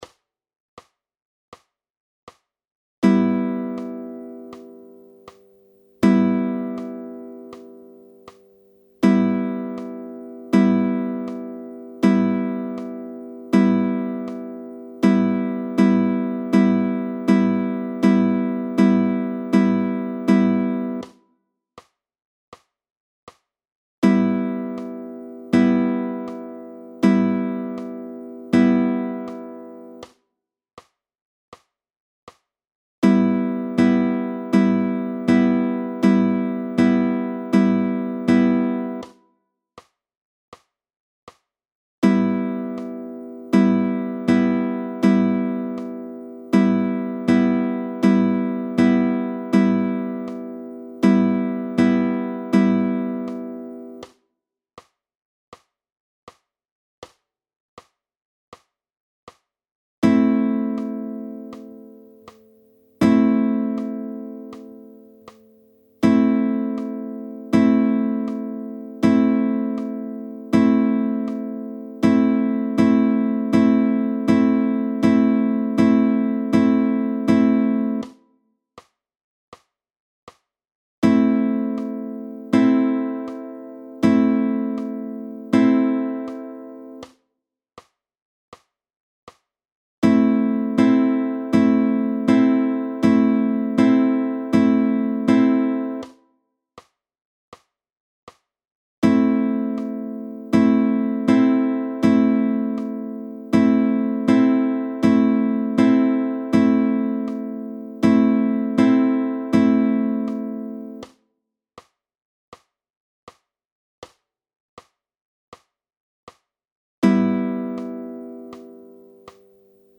3stimmige Akkorde
– Em, Am
I.) Ab- und Aufschläge mit Zeigefinger: PDF
Audio, 80 bpm: